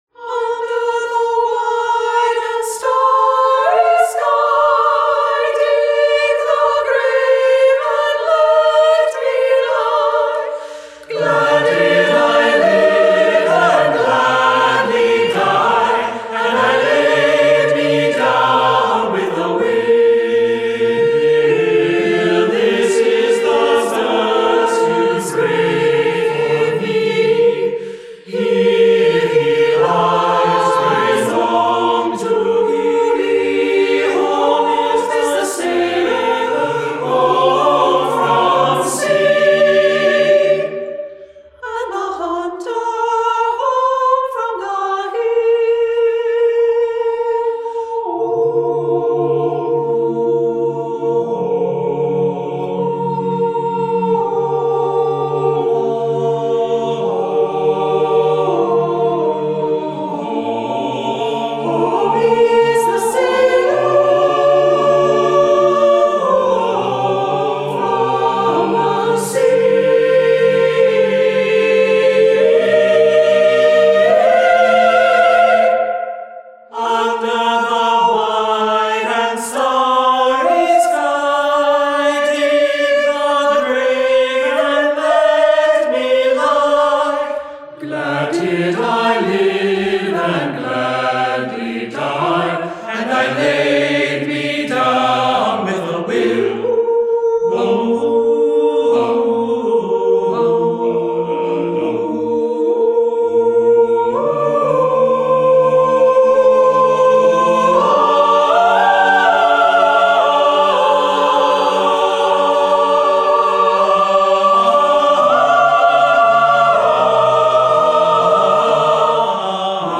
for SATB a cappella choir
Inspired by Irish folk music